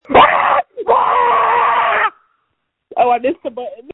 • When you call, we record you making sounds. Hopefully screaming.